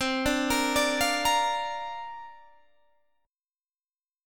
C7sus2sus4 chord